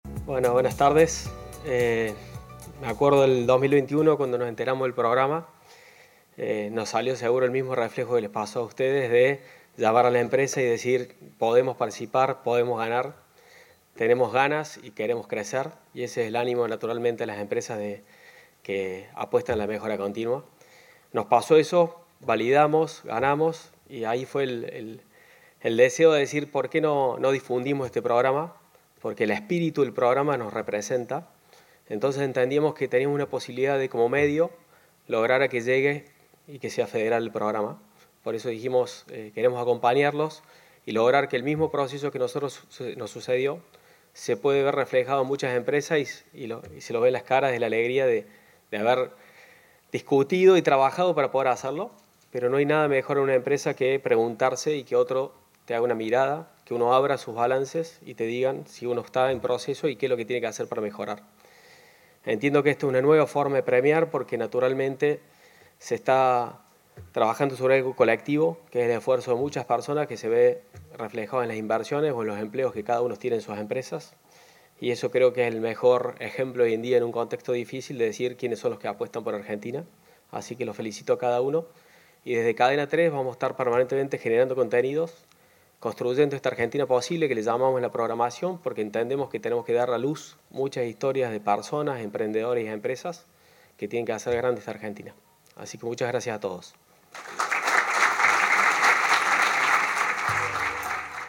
El discurso